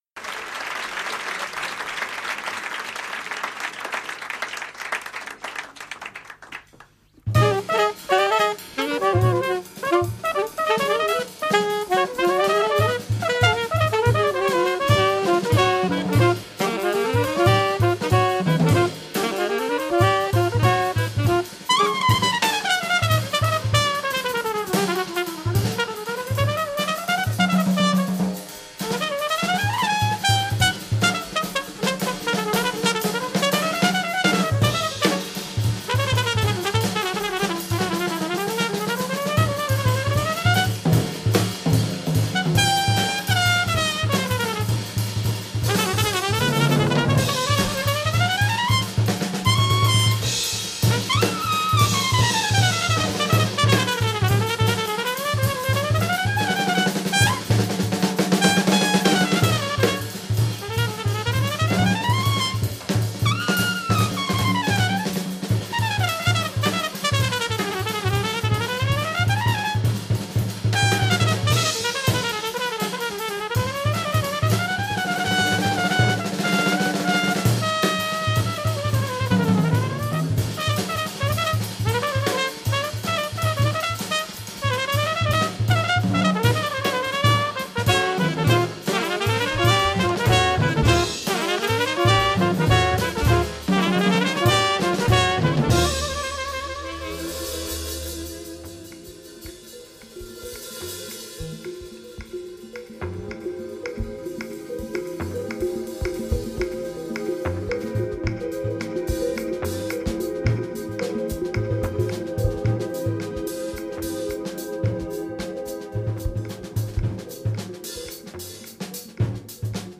Recorded at Pizza Express, Dean Street - London
live at Pizza Express, Dean Street – London